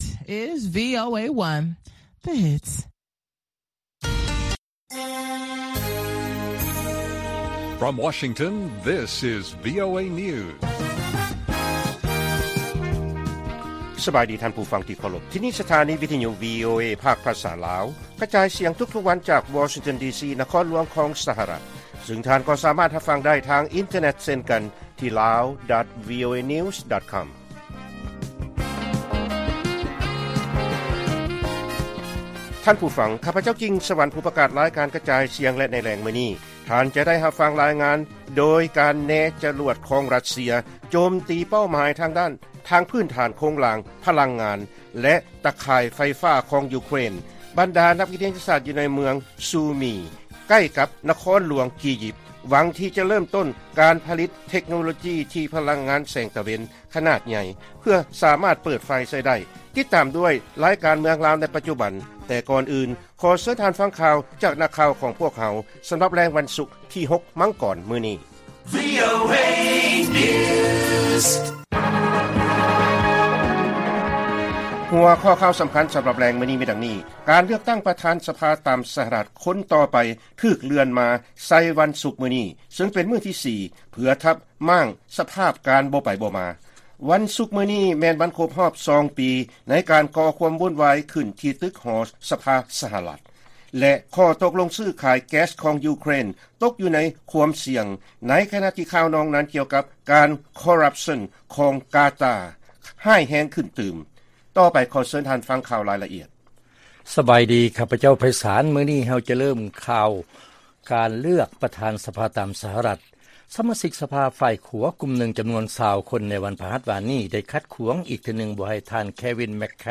ວີໂອເອພາກພາສາລາວ ກະຈາຍສຽງທຸກໆວັນ, ຫົວຂໍ້ຂ່າວສໍາຄັນໃນມຶ້ມີ: 1.